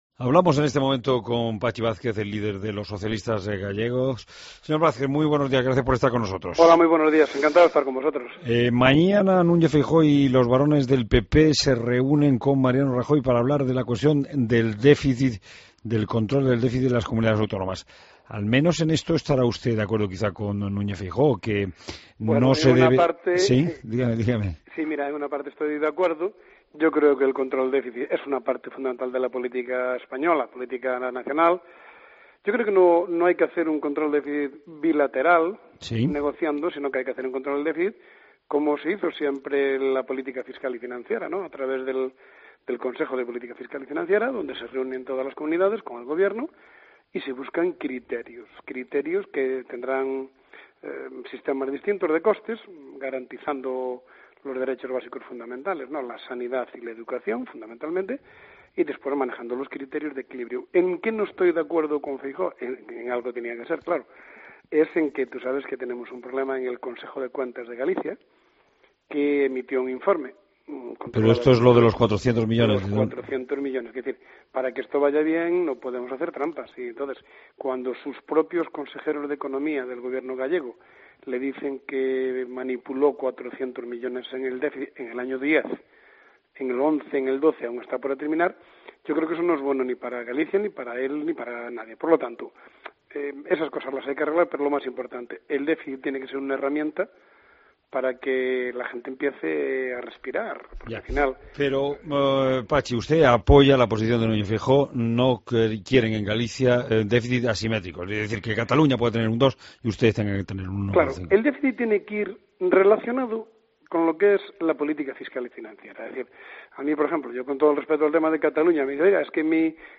Escucha la entrevista a Pachi Vázquez en La Mañana de COPE